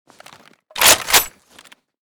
m82_unjam.ogg